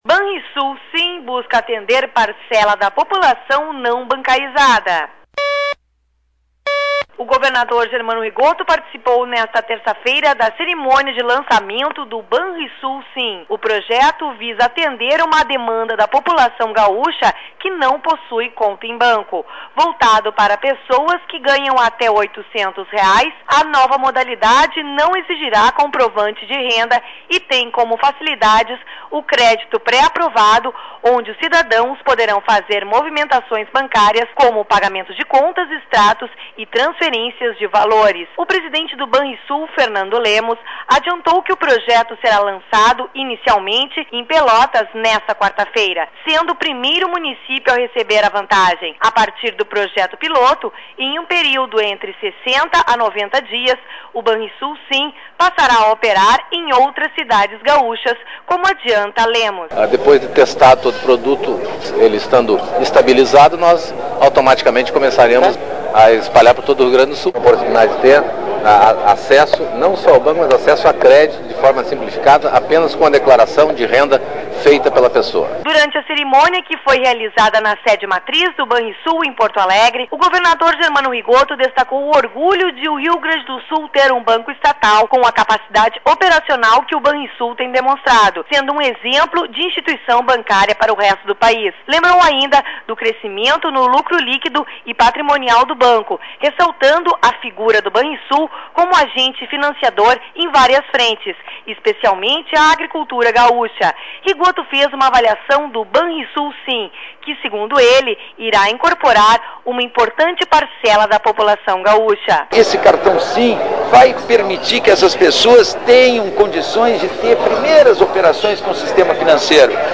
O governador Germano Rigotto participou nesta terça-feira da cerimônia de lançamento do Banrisul Sim. O projeto visa atender uma demanda da população gaúcha que não possui conta em banco.